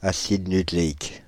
Prononciation
Prononciation (avec sigmatisme latéral): IPA: [a.ɬ͡sid ny.kle.ik] Accent inconnu: IPA: /a.sid.ny.kle.ik/ Le mot recherché trouvé avec ces langues de source: français Traduction 1.